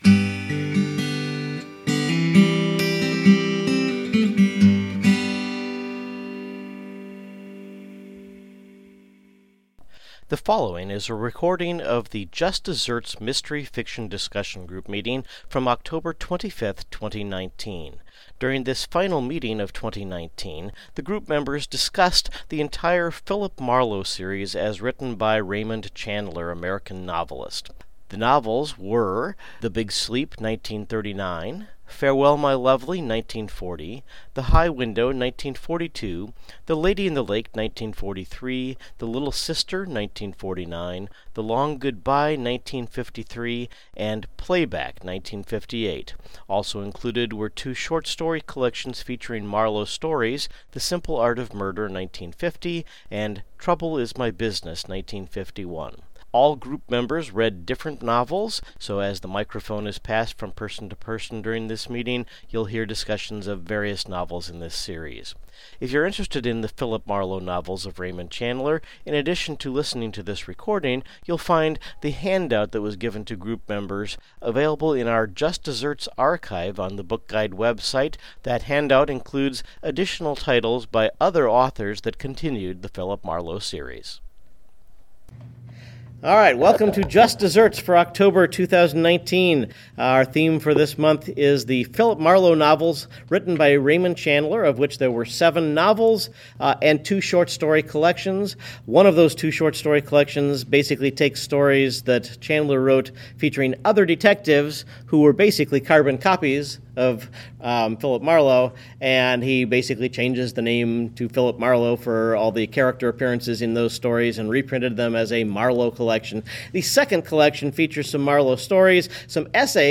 Participants in the Just Desserts Mystery Discussion Group share their thoughts about the Philip Marlowe series by Raymond Chandler.